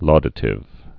(lôdə-tĭv)